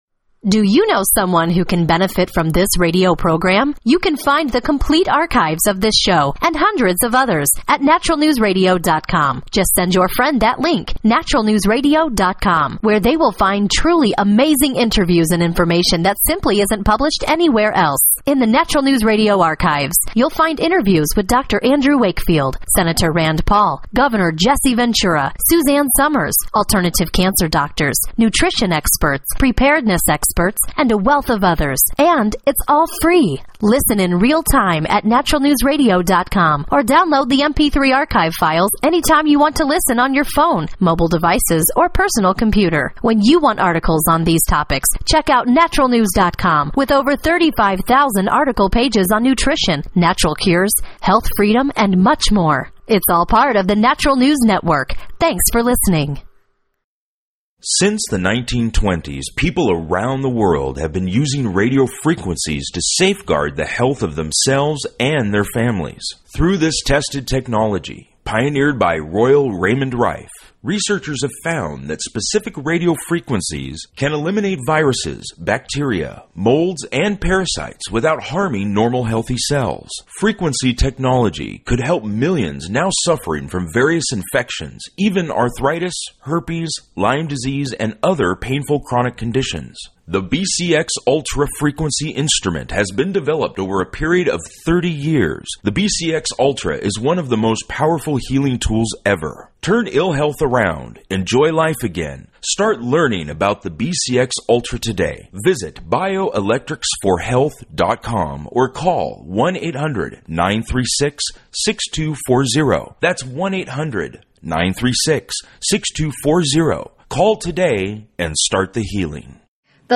Radio Interview and Gym Drama